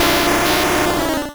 Cri de Gravalanch dans Pokémon Rouge et Bleu.